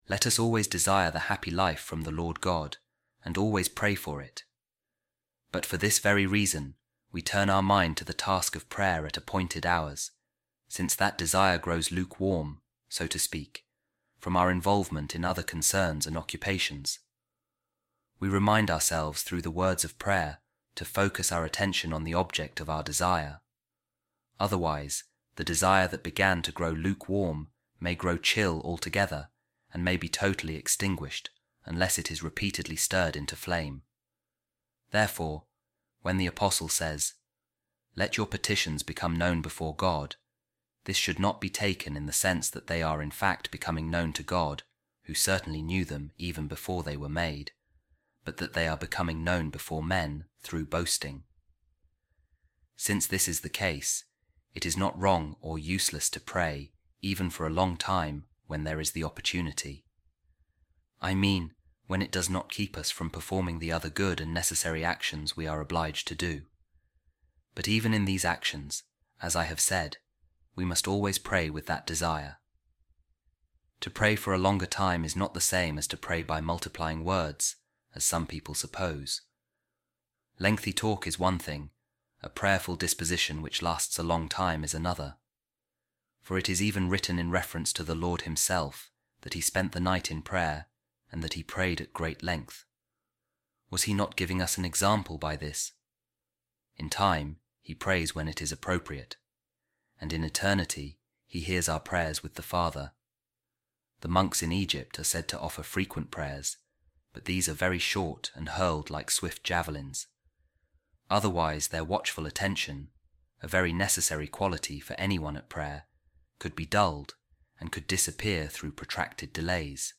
A Reading From The Letter Of Saint Augustine To Proba | Let Us Turn Our Mind To The Task Of Prayer At Appointed Hours